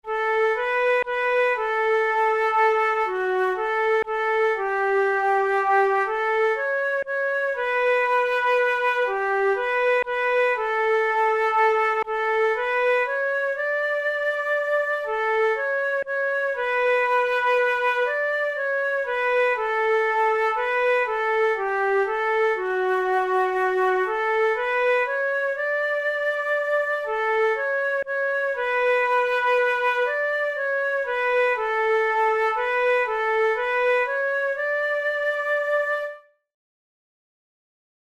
German folk song
Categories: Traditional/Folk Difficulty: easy